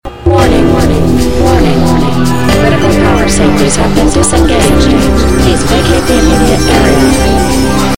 тревога